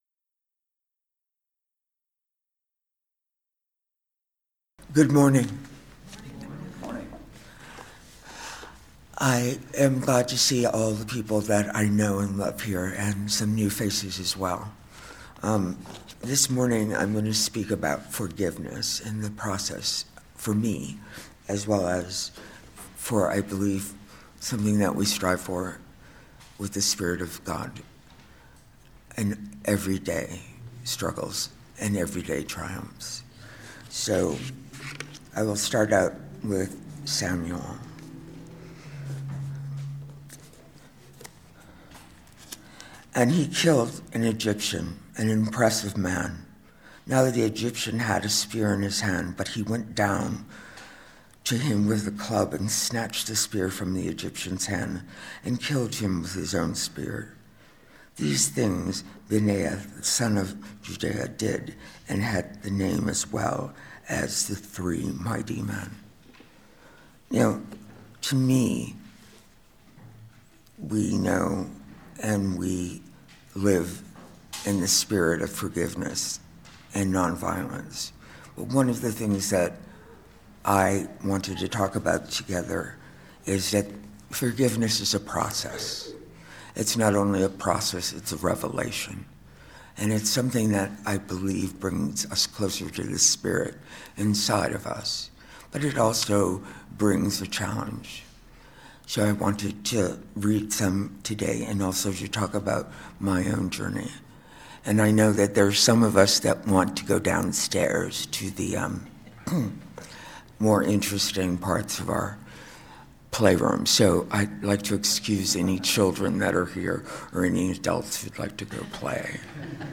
Listen to the most recent message from Sunday worship at Berkeley Friends Church.